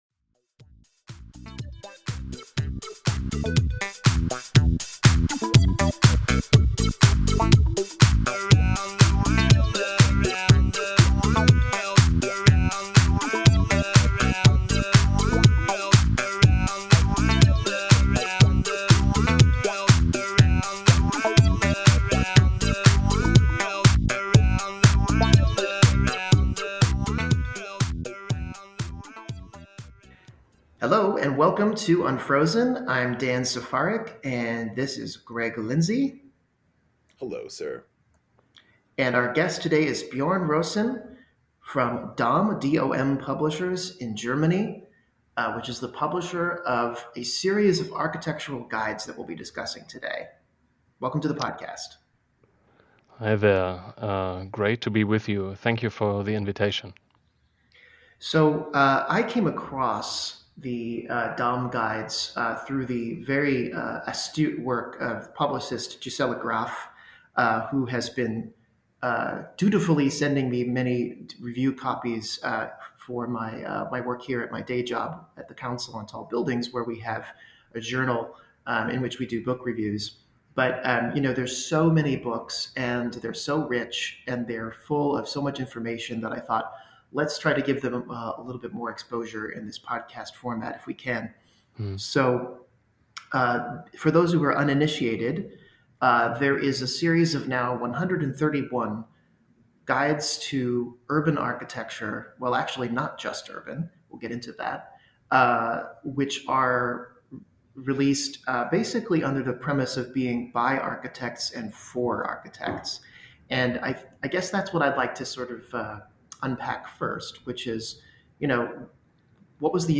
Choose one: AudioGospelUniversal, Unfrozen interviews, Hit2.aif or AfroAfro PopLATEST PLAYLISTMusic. Unfrozen interviews